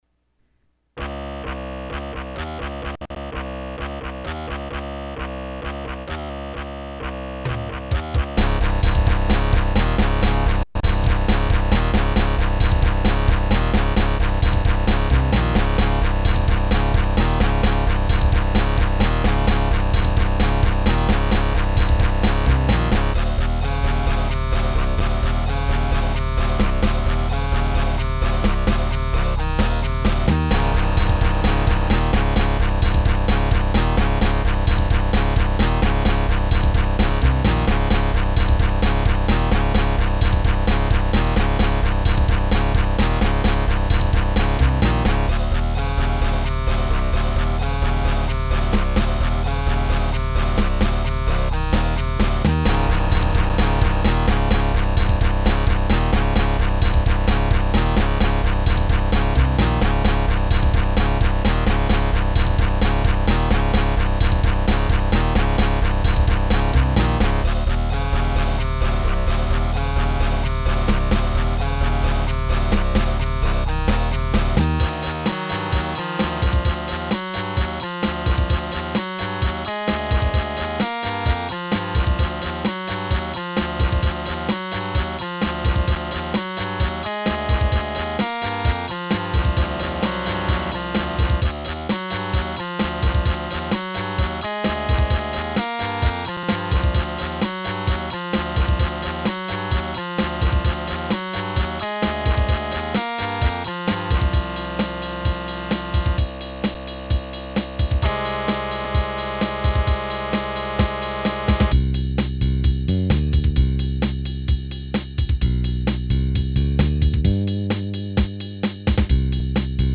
Y estan en MIDI, algun dia estaran grabadas.